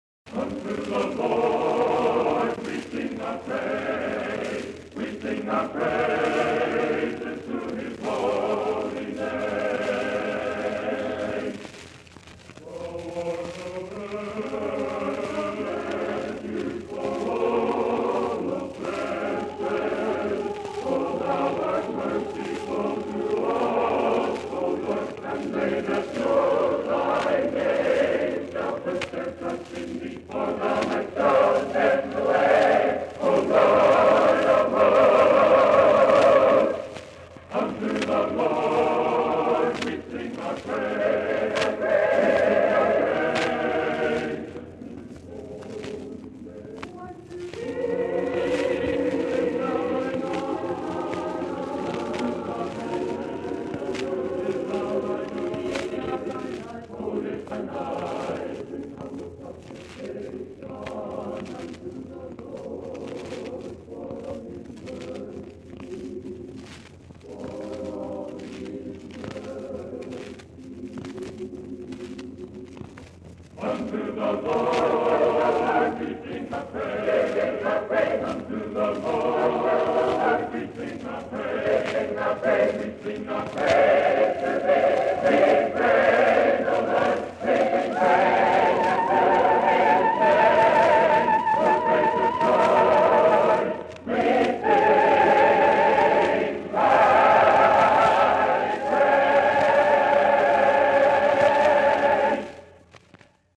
Bethany Peniel College (BPC) A Cappella Choir recordings from 1953-1954.